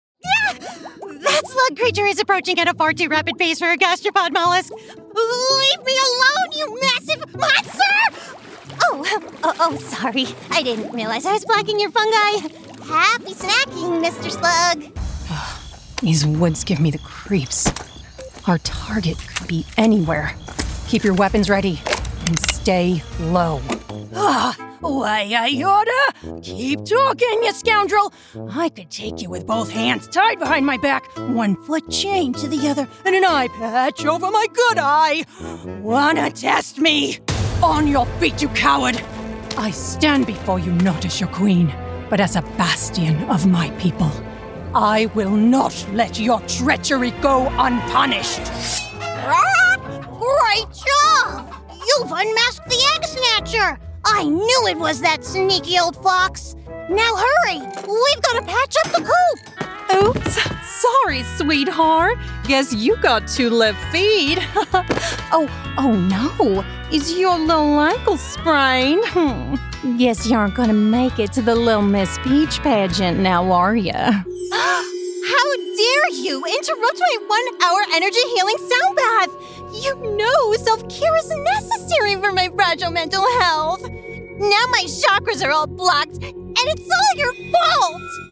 English (American)
Video Games
I specialize in authentic, conversational commercial reads with a warm and youthful tone. I have been described as having a friendly and relatable vocal quality.
Sennheiser MKH416